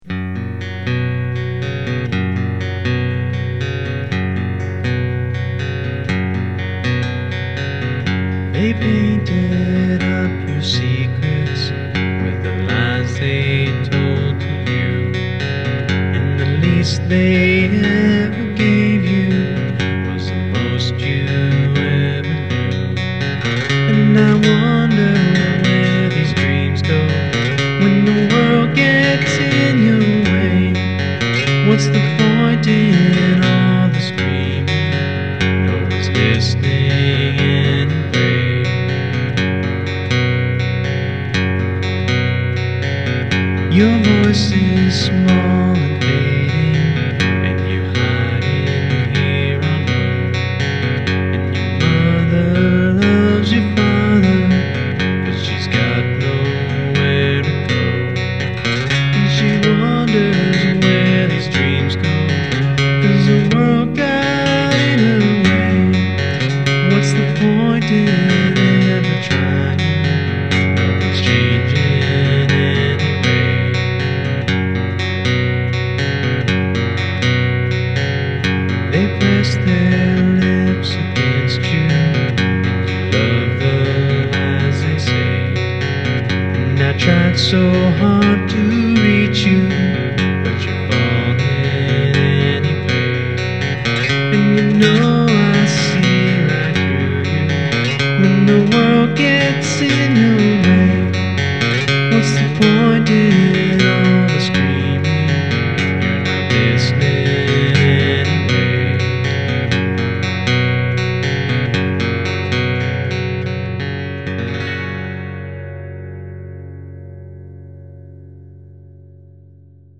• Acoustic and vocal (gag).